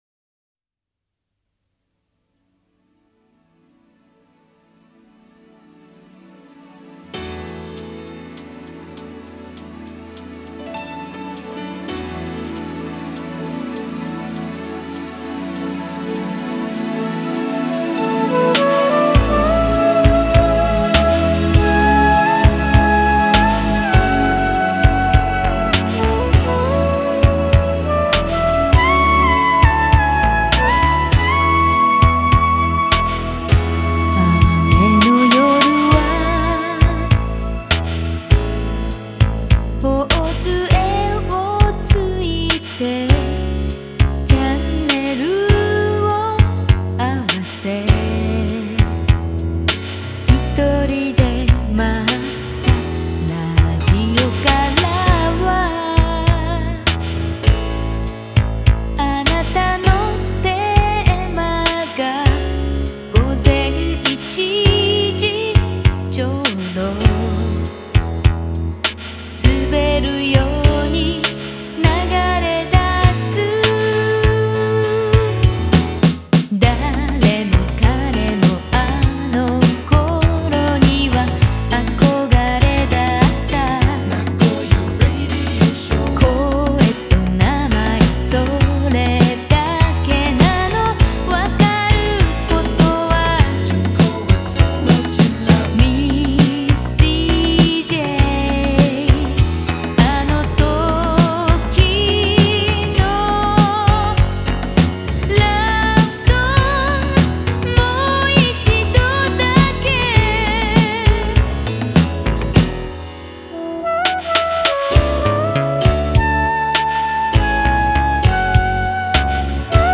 Обладает красивым и проникновенным высоким голосом.
Жанр: J-Pop, Ballad